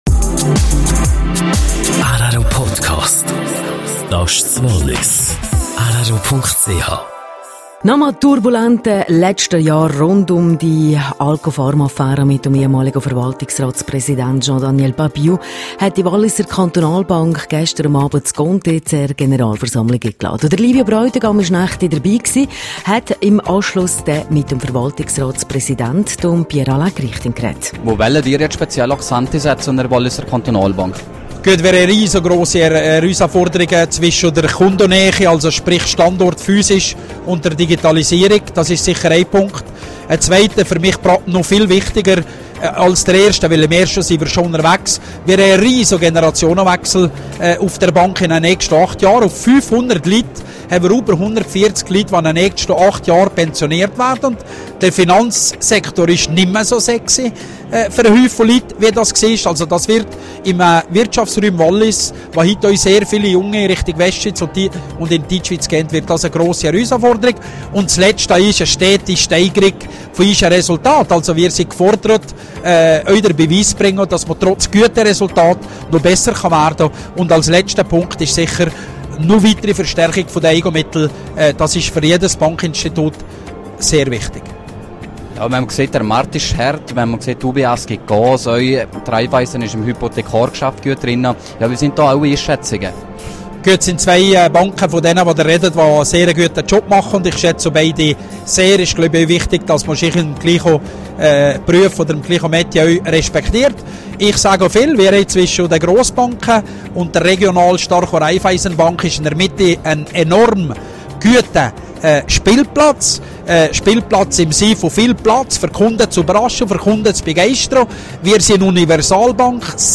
Bauarbeiten der BLS. Interview